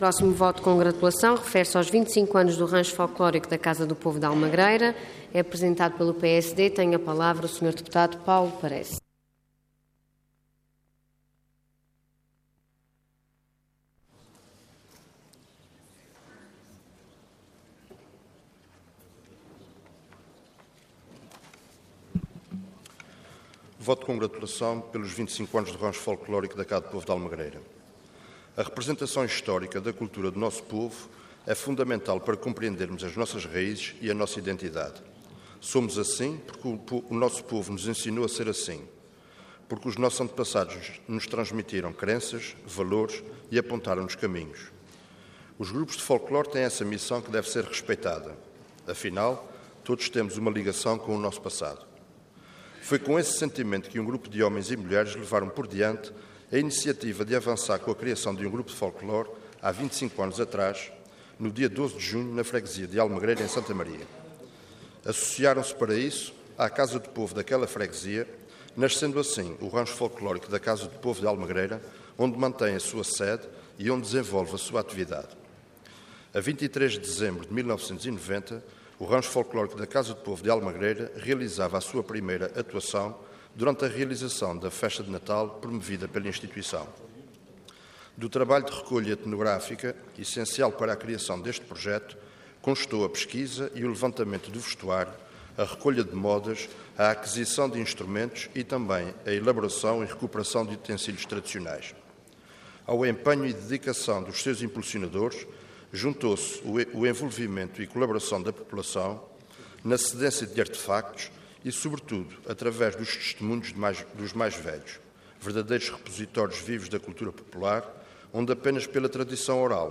Detalhe de vídeo 10 de dezembro de 2015 Download áudio Download vídeo Processo X Legislatura 25 Anos do Rancho Folclórico da Casa do Povo da Almagreira Intervenção Voto de Congratulação Orador Paulo Parece Cargo Deputado Entidade PSD